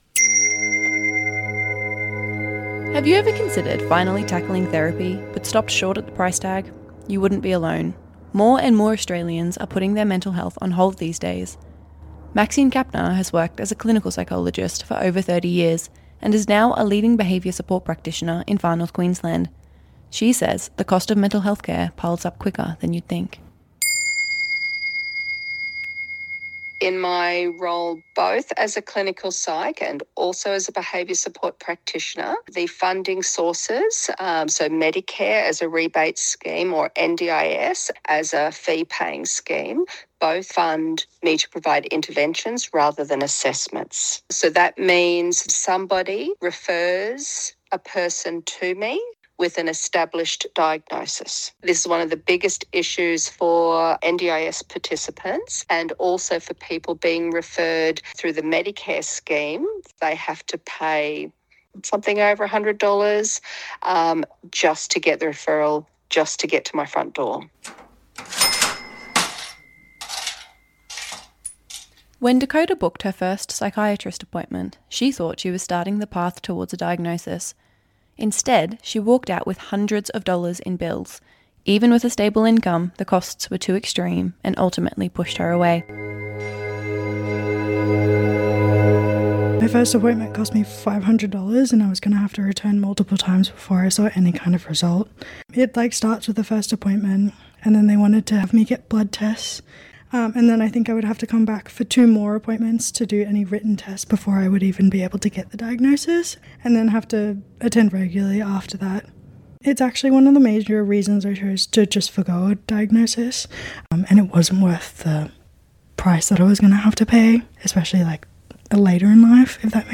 and get first- hand accounts from several young Australians who hav been forced to forego therapy entirely.